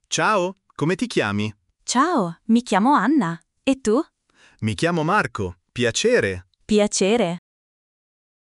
🗣 Dialogo 1 – Incontro informale